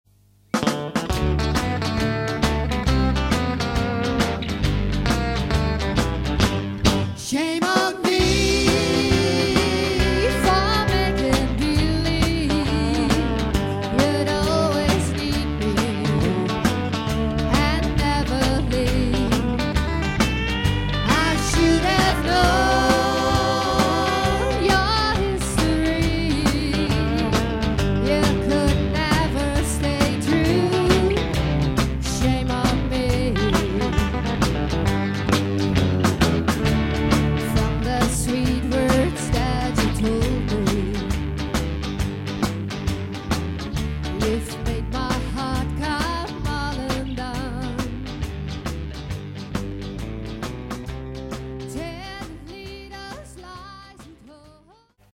Guitar (solo)
Guitar (Rhythm)
Guitar (bass)
Drums
Singer (female)